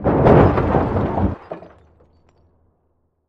wallcrash.ogg